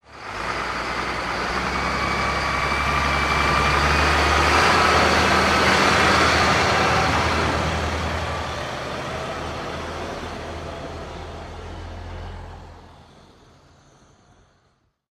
tr_sbus_driveby_01_hpx
Exterior point of view of a school bus as it drives, idles and shuts off. Vehicles, School Bus Bus, School Engine, Motor